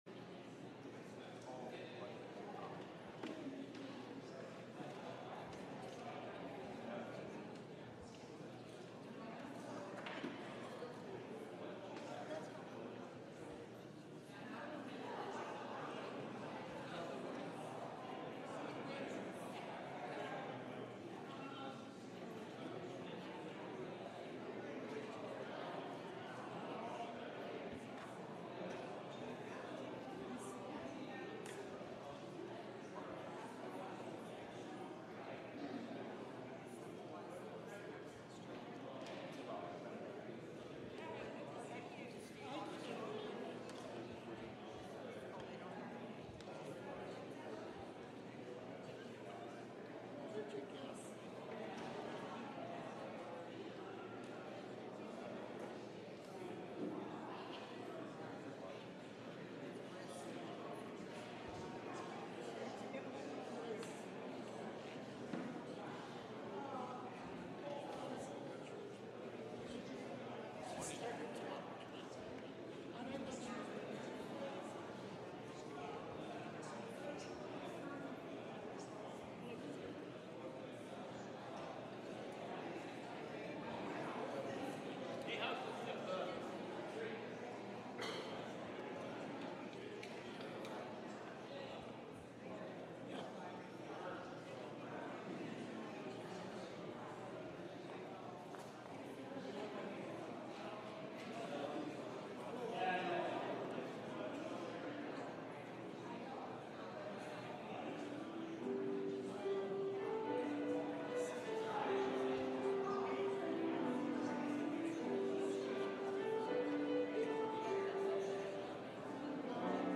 LIVE Morning Worship Service - The Imperfect Anointed: Motive, Means, and Opportunity